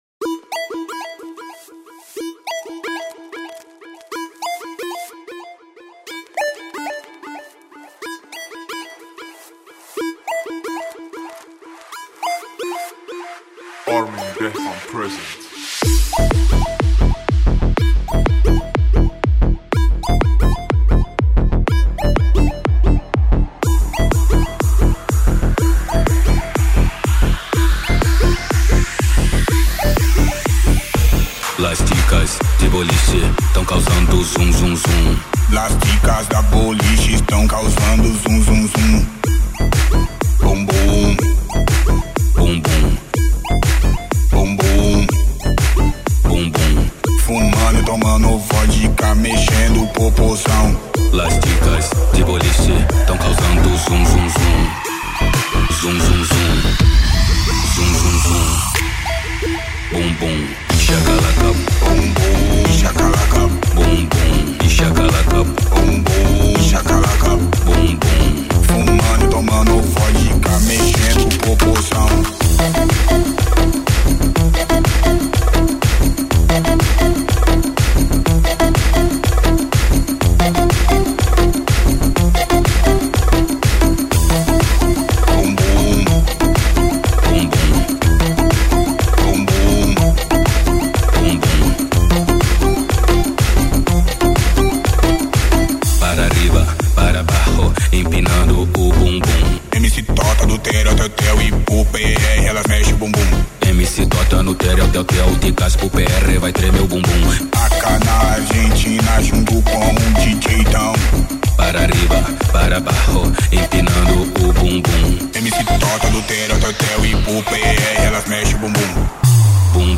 دانلود ریمیکس شاد جدید
ریمیکس بندری خارجی ریمیکس خارجی با ریتم بندری
ریمیکس شاد خارجی برای رقص و پارتی